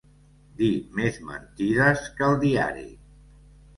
El substantiu «mentides» es pot pronunciar 'mintides'. Ésser molt mentider.